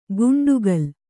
♪ guṇḍugal